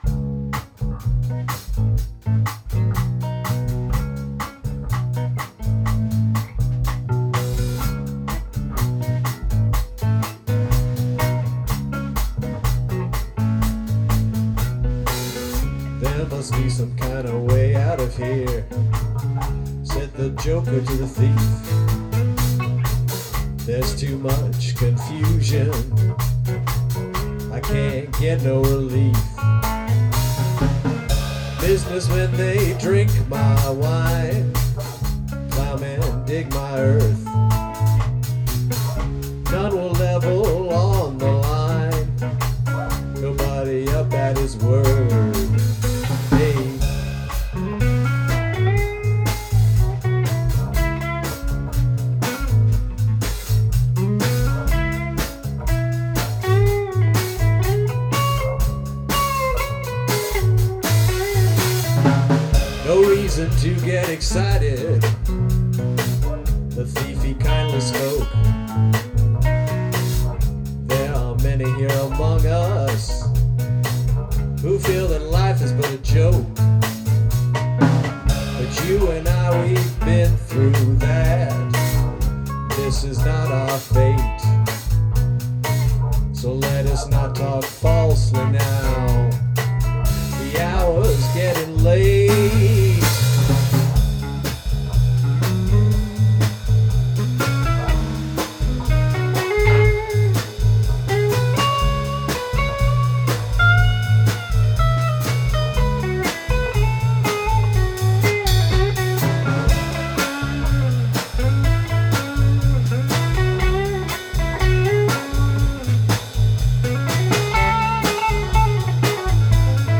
guitarist
bassist
drummer
All Along the Watchtower (audio only) Bob Dylan Band Rehearsal